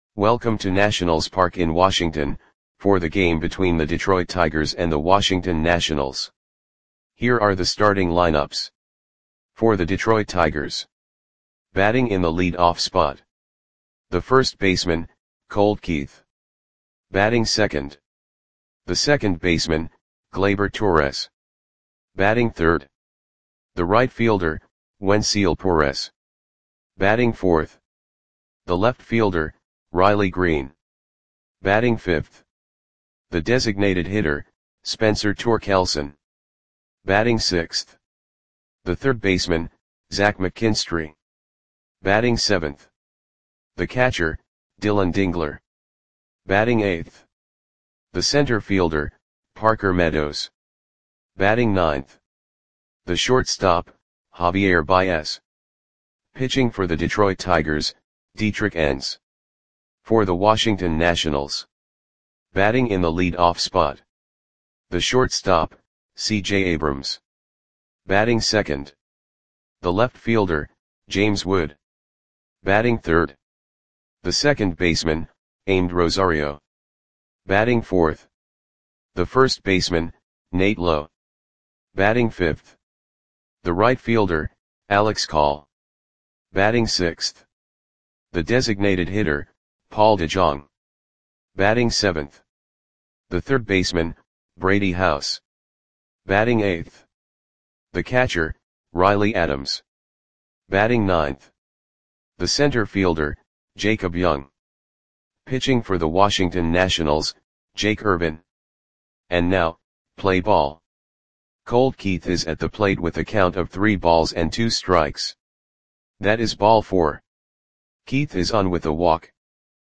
Click the button below to listen to the audio play-by-play.